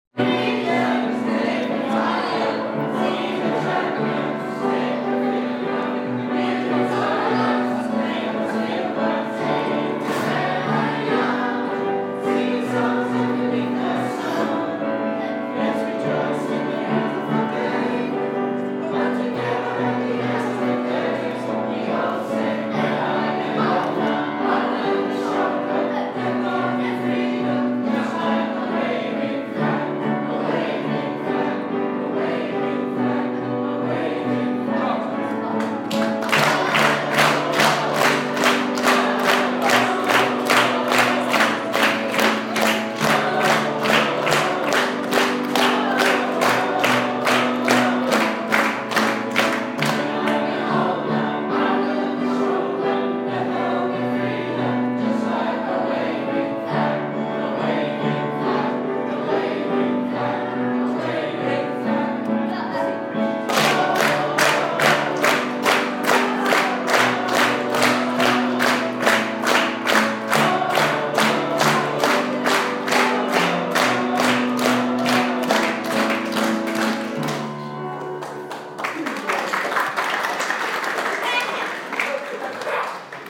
Lansdowne Singing Assembly